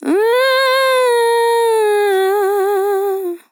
TEN VOCAL FILL 24 Sample
Categories: Vocals Tags: dry, english, female, fill, sample, TEN VOCAL FILL, Tension